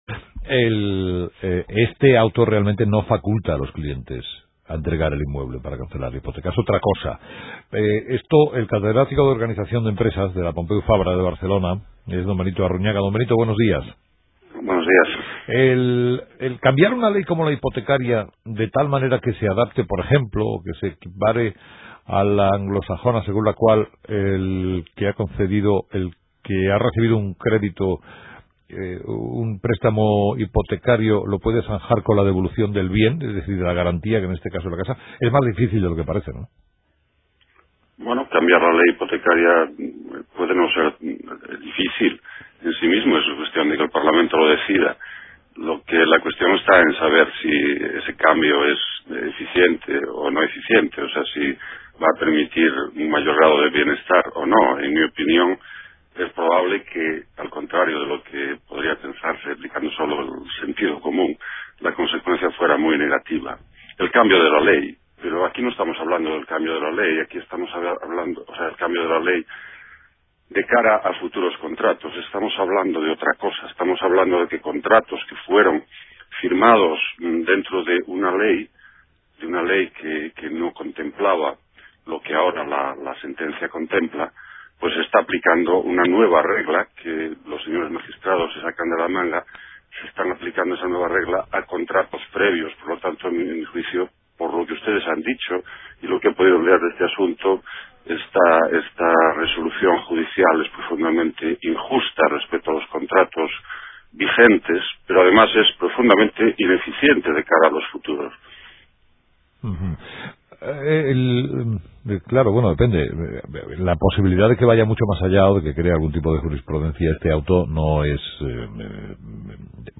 Entrevistado: